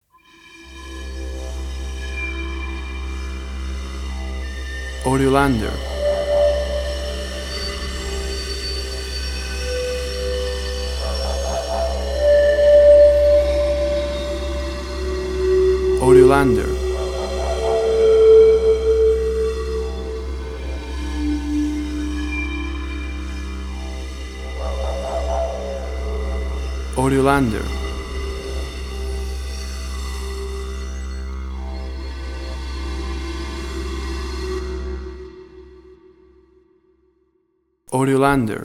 Ethereal airy with zampoñas and didgeridoo
Tempo (BPM): 60